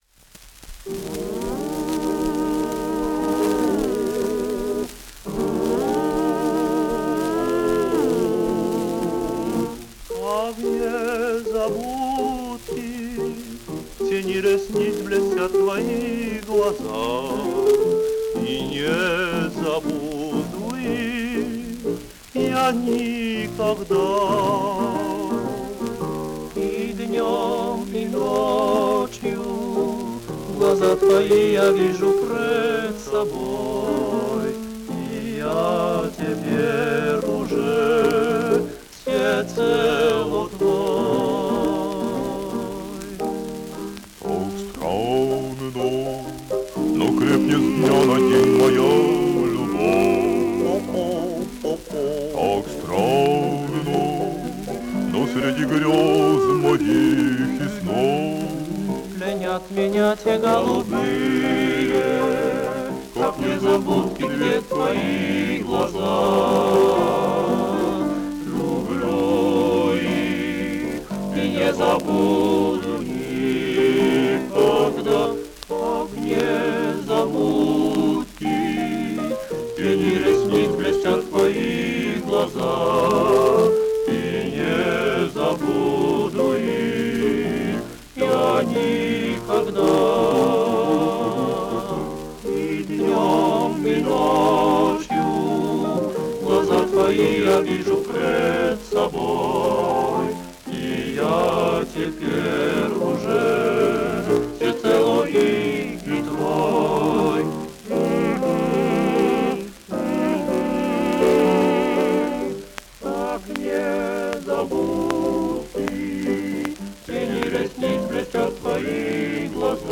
танго
Партия фортепиано